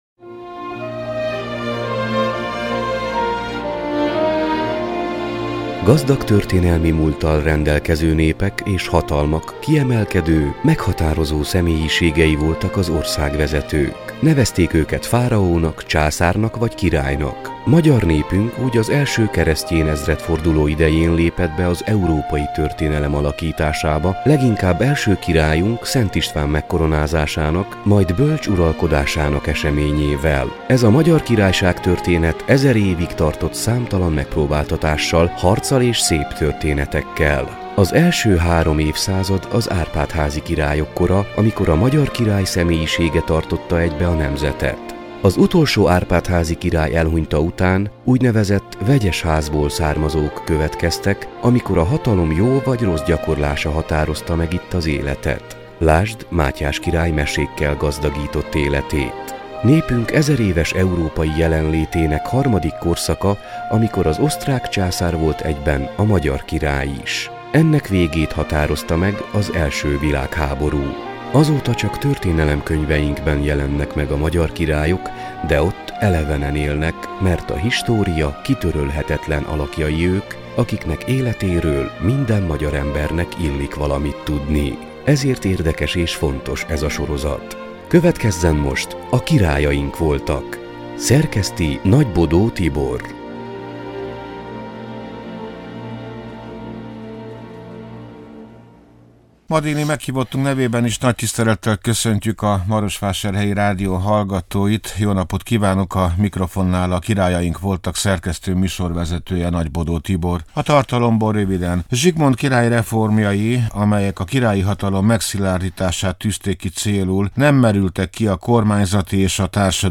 beszélget.